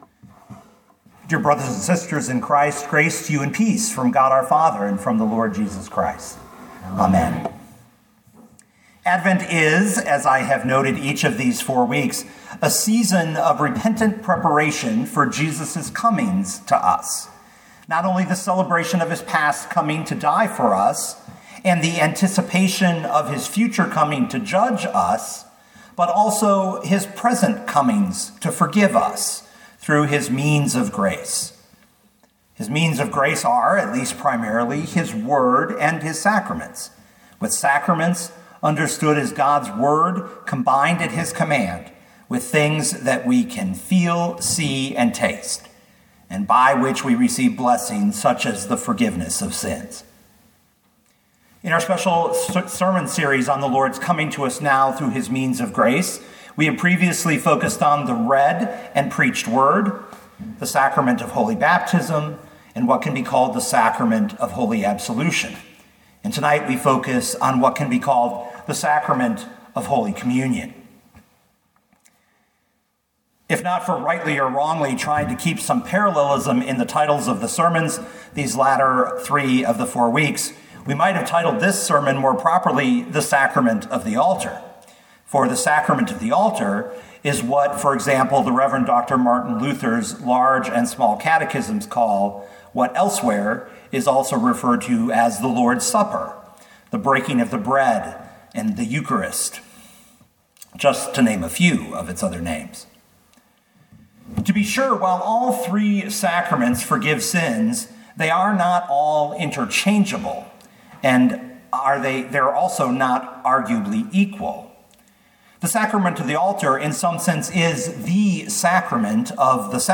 Sermons
Midweek Advent IV, December 22, 2021